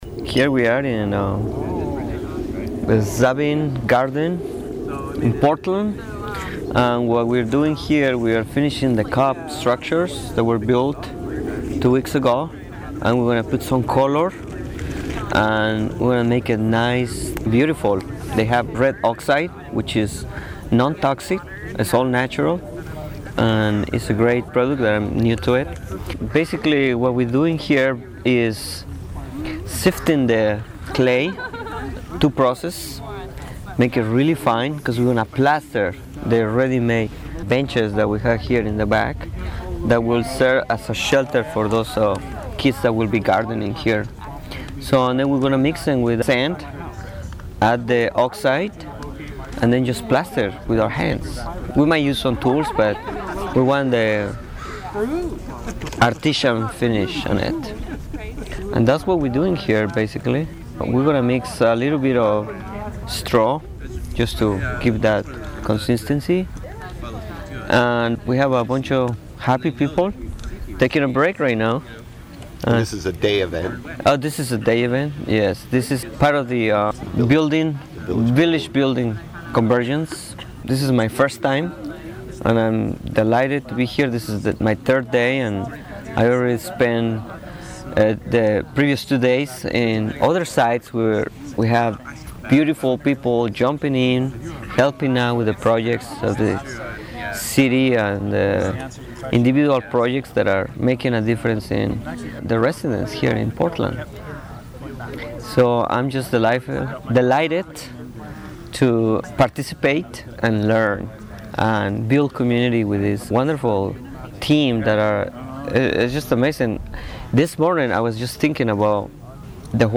2009 Village Building Convergence